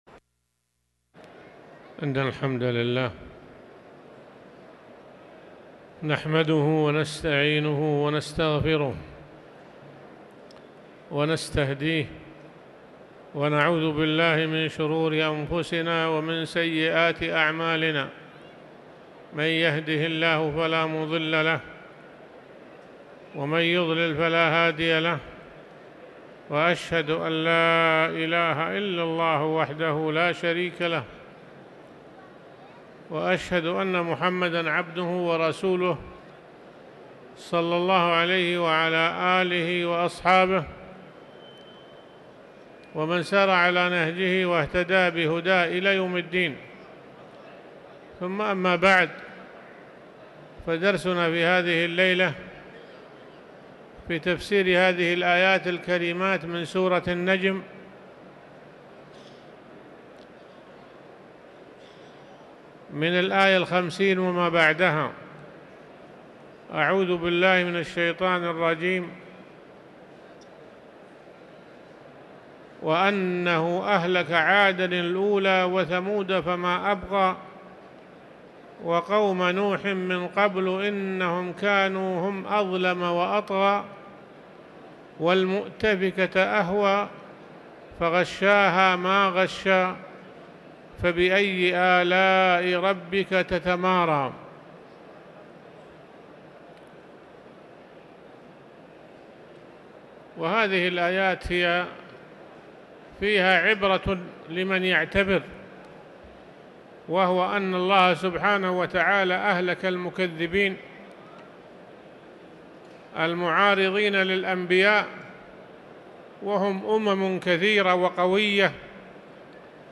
تاريخ النشر ٦ جمادى الآخرة ١٤٤٠ هـ المكان: المسجد الحرام الشيخ